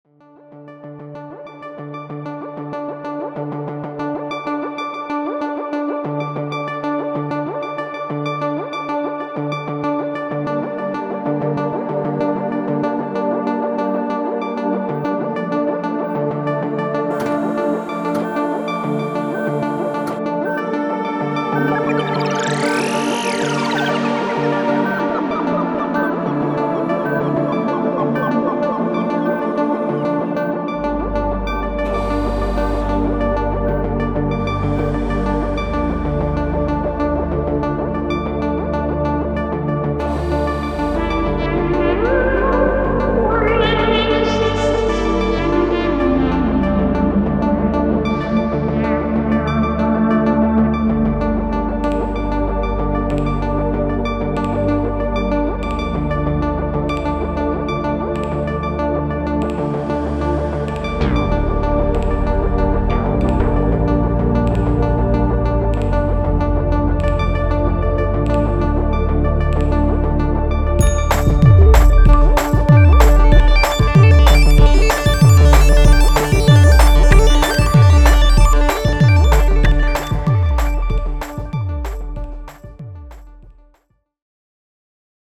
выход одного из 500 (250 стерео) каналов аналоговой машины и возврат с нее на вход АЦП.